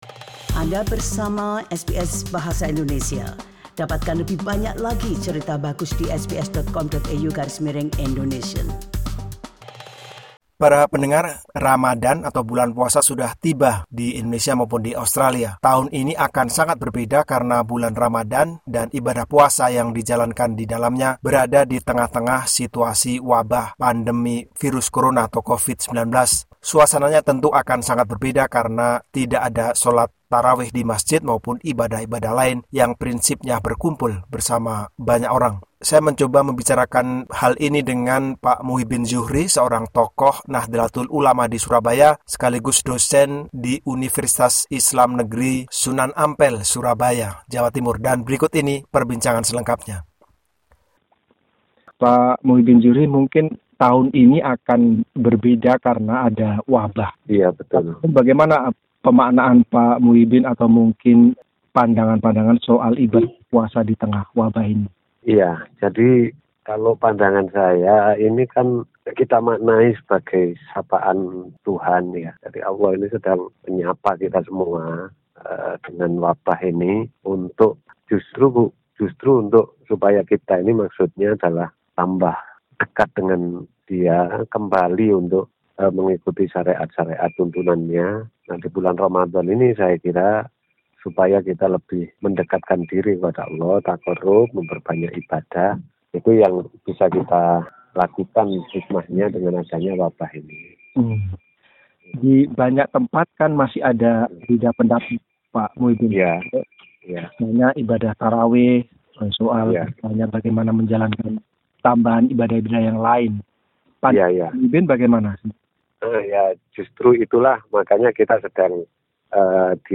How should we respond and what is the meaning of this epidemic to our faith? Follow our conversation